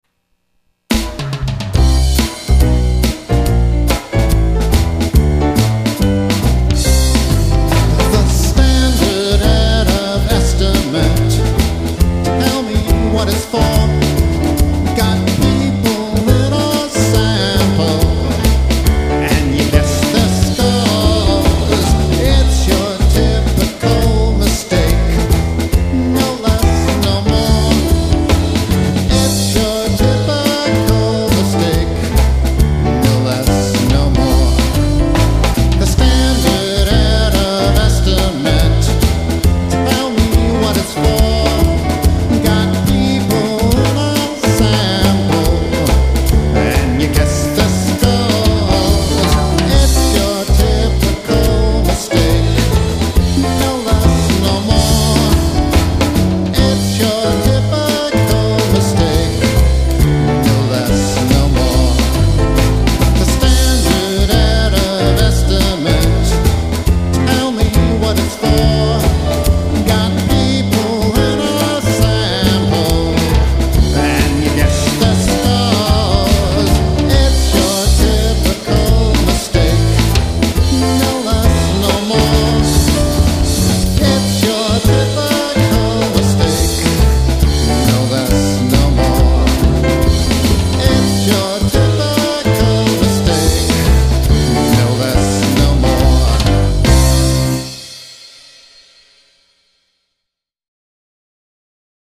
This is a page of stat music.